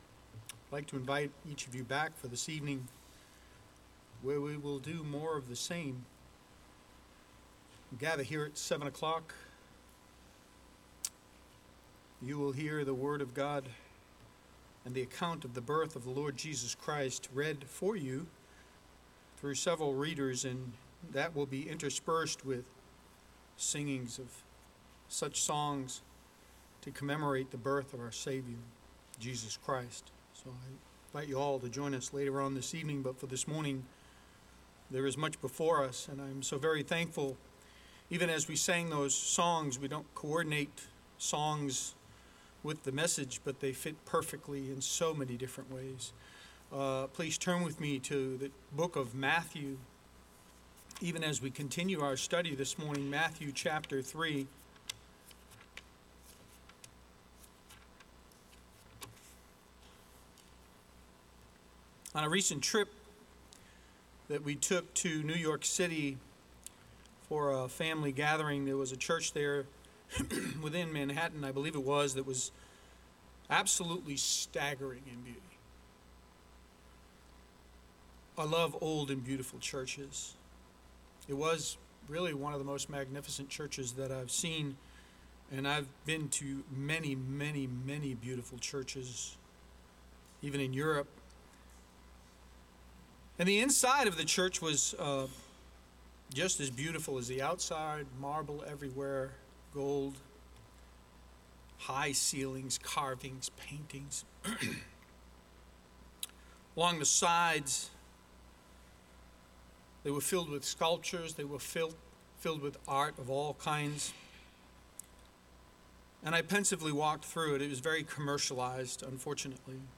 Trinity Bible Church of Fredericksburg , Sermons, Expository Preaching, Topical Sermons, Gospel of Matthew